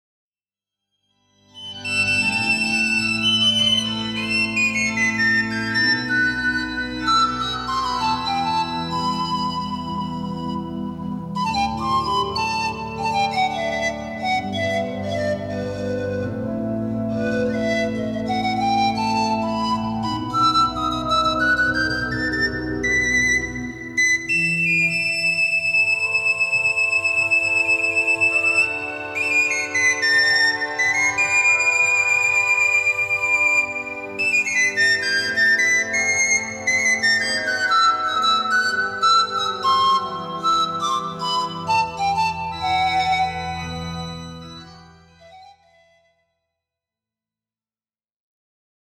Instrumental (217)
Format :MP3 256Kbps Stéréo